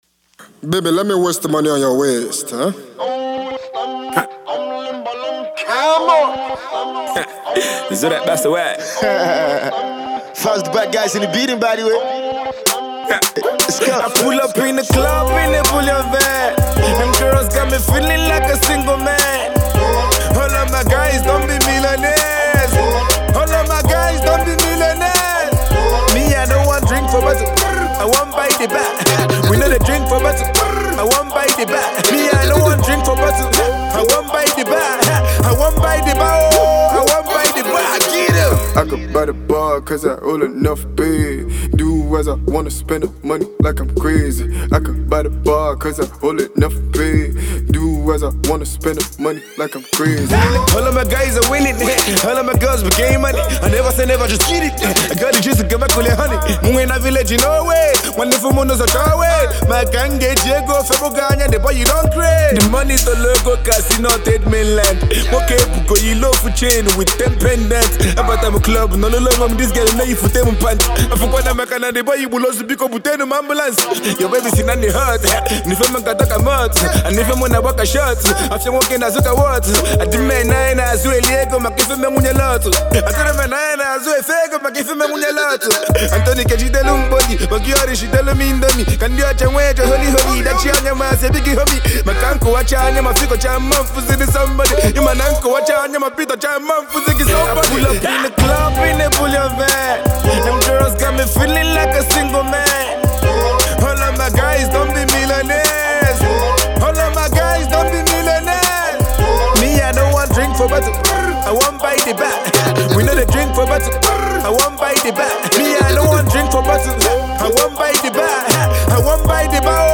On this one, both rappers are certainly on point.